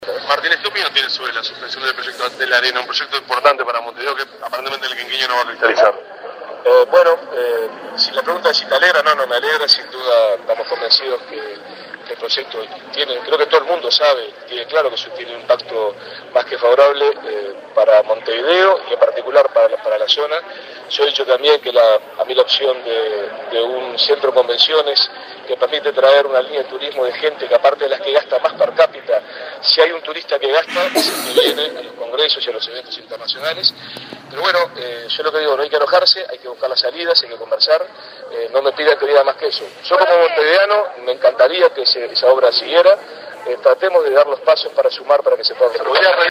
El intendente de Montevideo, Daniel Martínez, disertó en la mañana de este viernes sobre la suspensión de las obras del Antel Arena.
Las palabras de Daniel Martínez